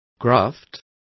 Complete with pronunciation of the translation of graft.